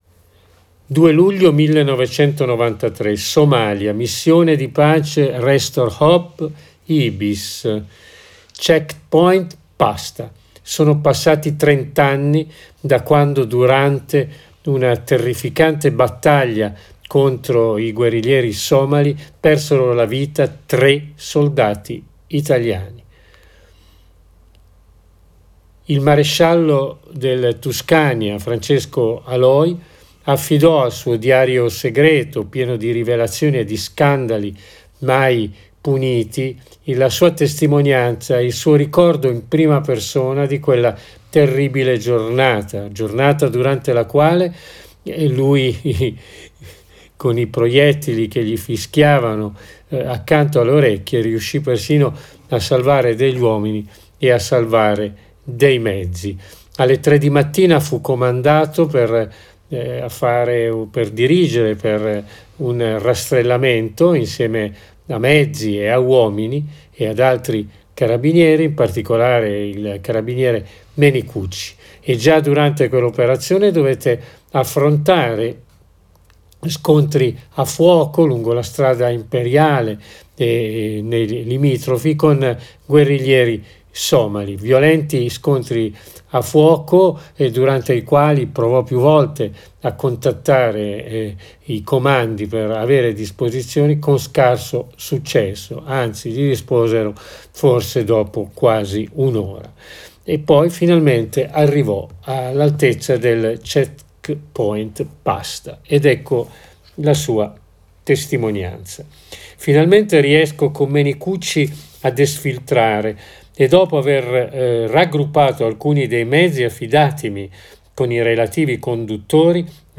Nell’audio qui sotto c’è la lettura di una parte del racconto